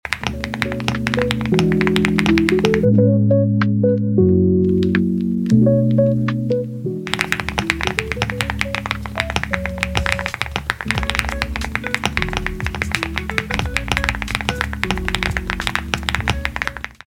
Today’s keyboard asmr is made sound effects free download
Today’s keyboard asmr is made possible by the cutest kitty switches 🐾 These switches are still in the works by a friend of mine so they are not yet available but I couldn’t resist sharing them! I’ll update this post as well as my stories once they make their way to international vendor sites 🤍 The kitty switches are deep sounding in my opinion and make a really good option for a creamy sound profile.